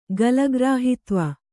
♪ galagrāhitva